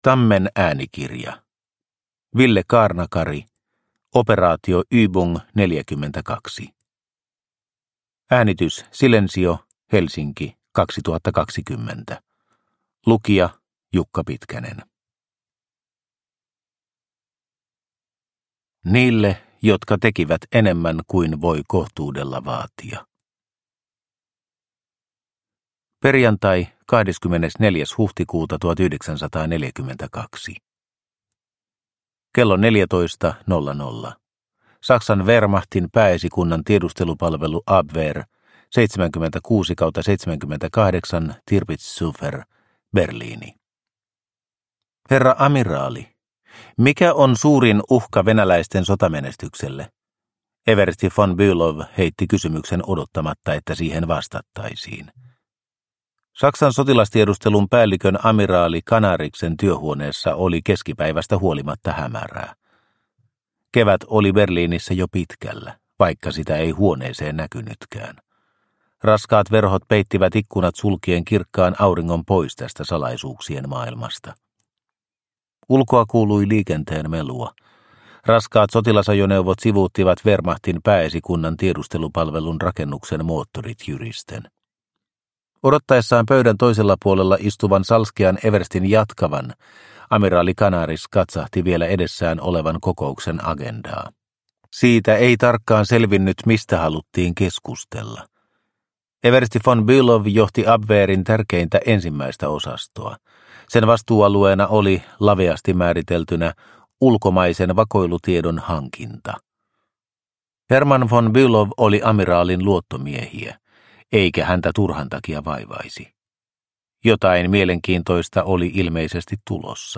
Operaatio Übung -42 – Ljudbok – Laddas ner